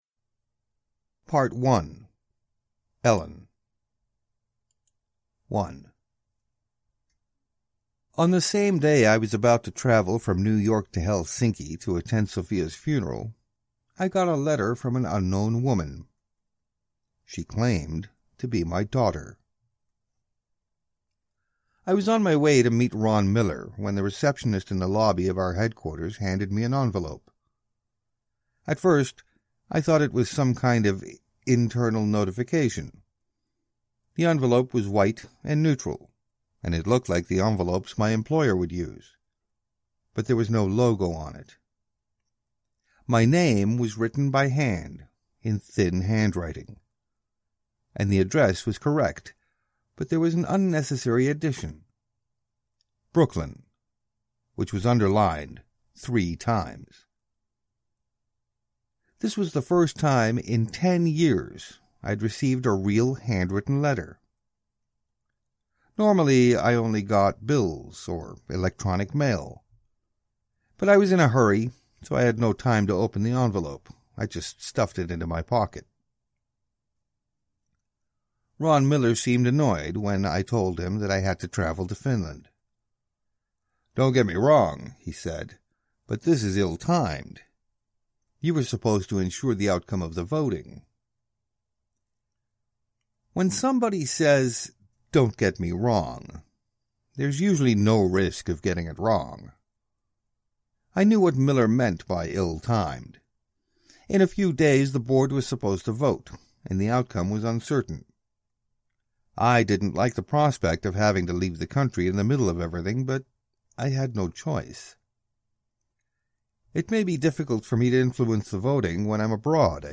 Ellen's Song – Ljudbok – Laddas ner